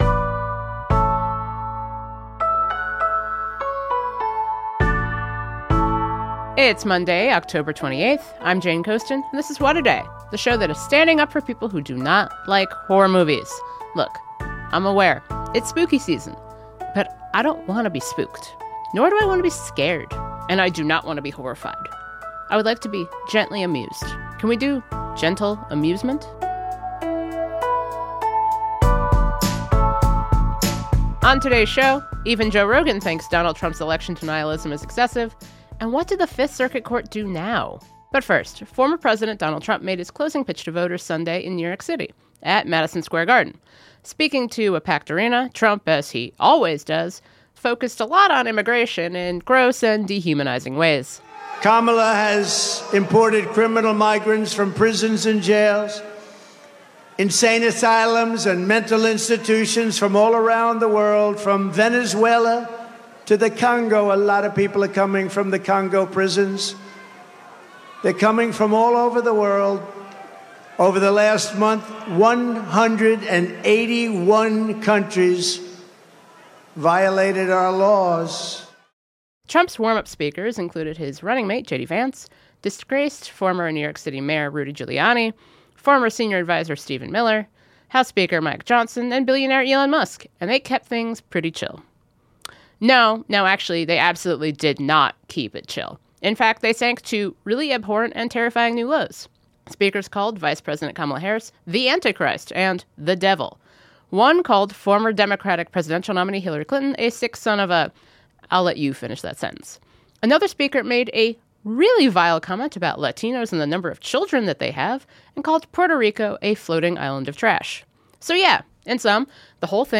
Maryland Democratic Gov. Wes Moore stops by to talk about the role of campaign surrogates in the final stretch to Election Day.